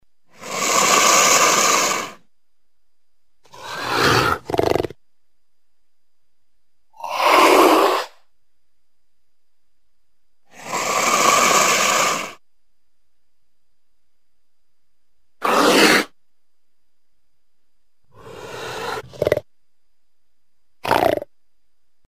Шипение пумы